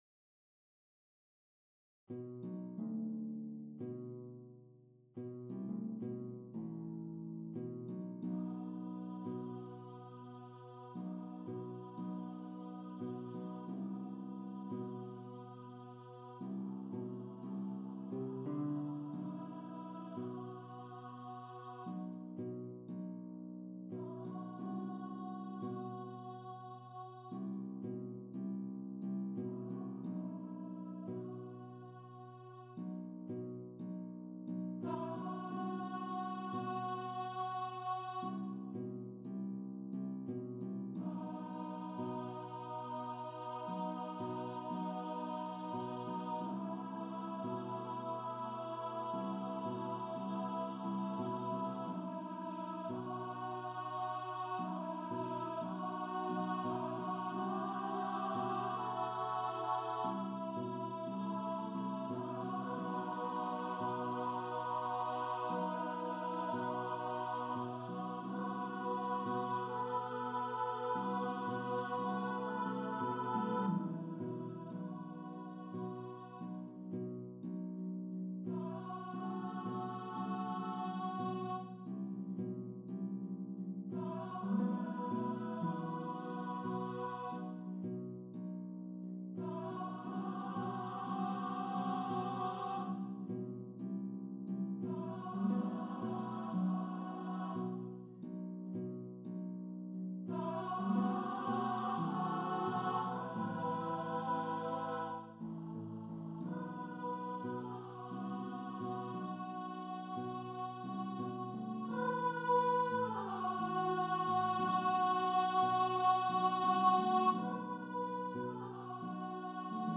Below are some resources about the Wyndreamas choir and harp piece.
Here is the MP3 of the score playback from Sibelius: